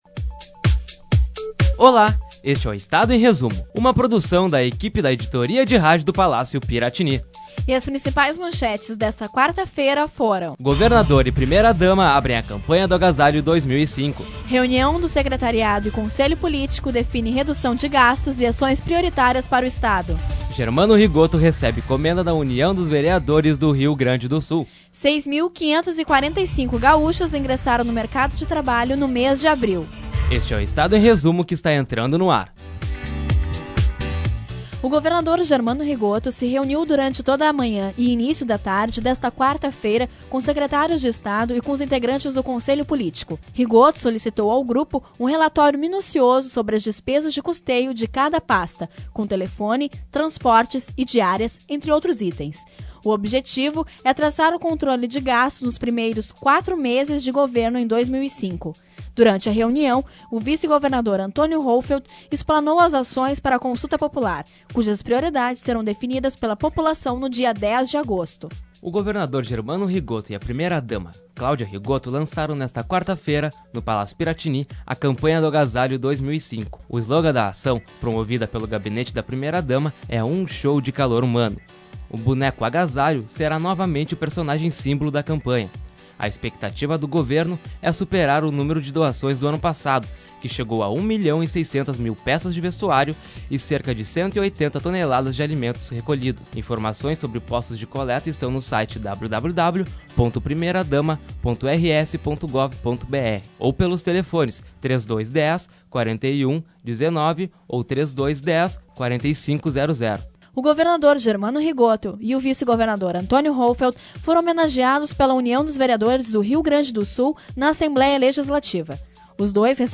2005-05-18-resumo-de-noticias.mp3